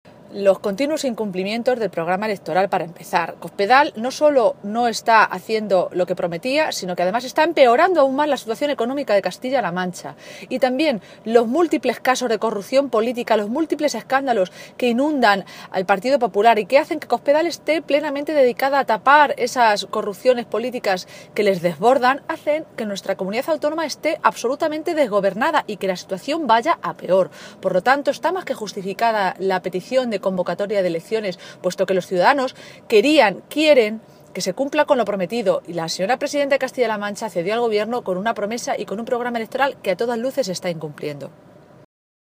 Maestre se pronunciaba de esta manera esta mañana, en una comparecencia ante los medios de comunicación en la capital de Castilla-La Mancha, donde hoy coincidía con la reunión del comité nacional de dirección del PP.
Cortes de audio de la rueda de prensa